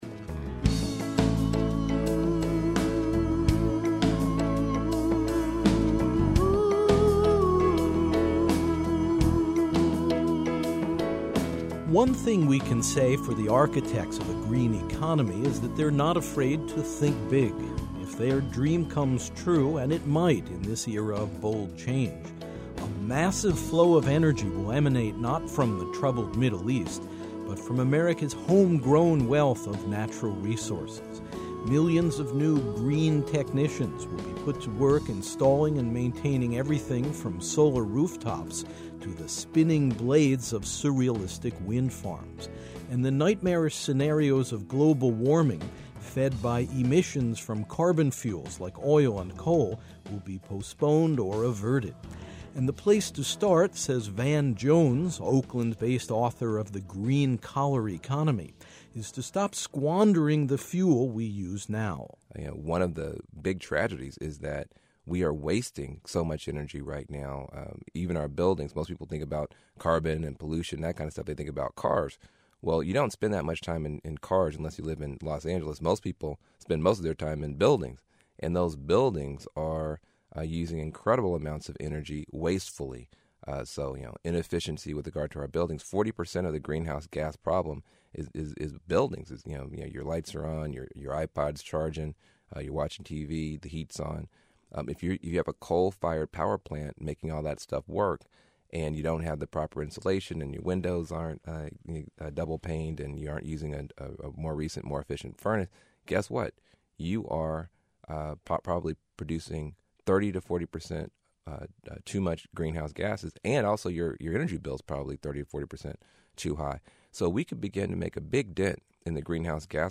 In the coming green economy, utilizing this energy will generate a surge of new employment, while combating climate change and providing additional energy independence for the United States. This program offers a brief history of American environmental concerns and features the voices of several leading lights in the current effort to protect the planet by relying on sustainable sources of energy.